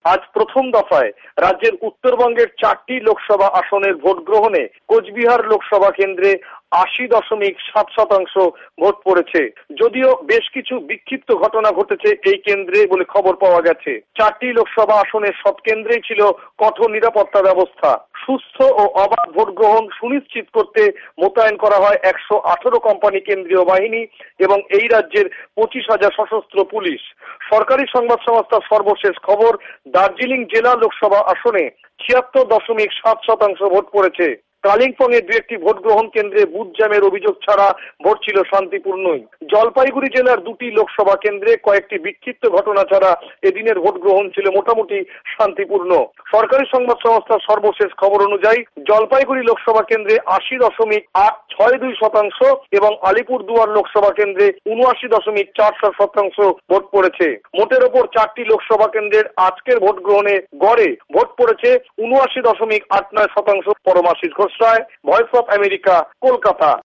কলকাতা সংবাদদাতাদের রিপোর্ট